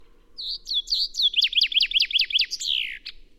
Большинство песен зяблика устроено так: вначале идет одна или несколько разных трелей (серий одинаковых нот), а в конце звучит заключительная громкая фраза — «росчерк».
Представьте себя Питером Марлером, послушайте записи песен шести зябликов и подберите для каждой из них трели и «росчерк» из предложенных схем.
chaffinch6.mp3